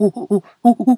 monkey_2_chatter_15.wav